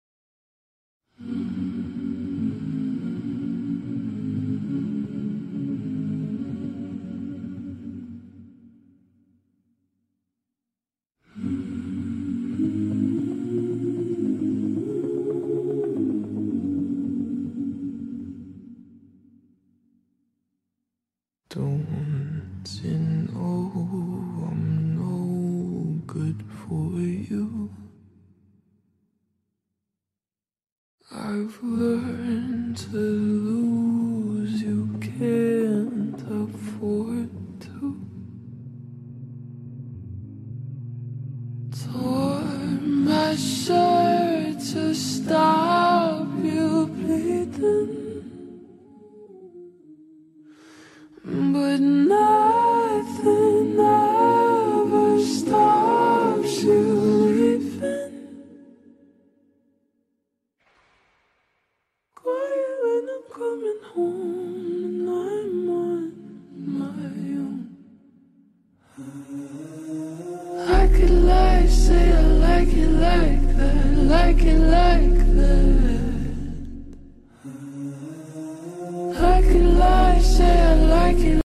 Sleep wagging is the cutest thing 😫